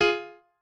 piano8_21.ogg